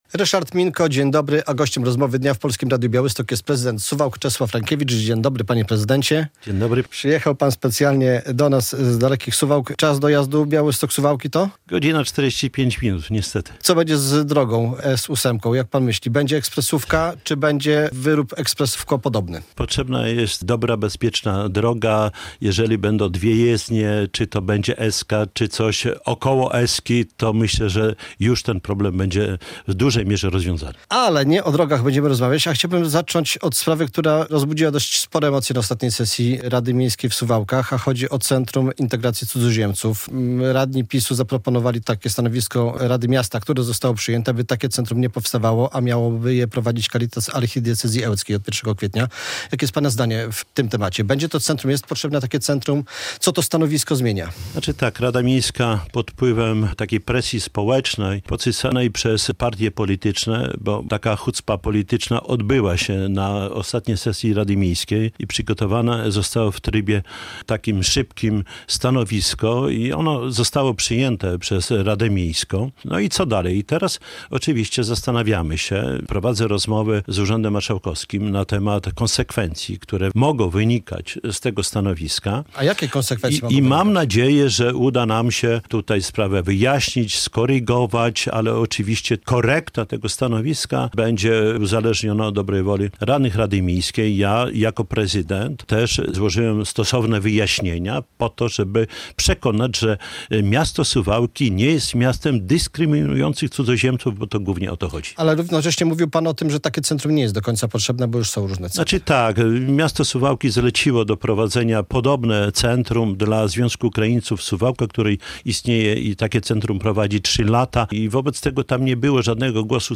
Radio Białystok | Gość | Czesław Renkiewicz - prezydent Suwałk
"Suwałki nie są miastem dyskryminującym cudzoziemców" - przekonuje prezydent Suwałk Czesław Renkiewicz. W ten sposób w Rozmowie Dnia Polskiego Radia Białystok odniósł się do niedawnej decyzji radnych, którzy przyjęli stanowisko sprzeciwiające się tworzeniu Centrum Integracji Cudzoziemców prowadzonego przez Caritas Archidiecezji Ełckiej.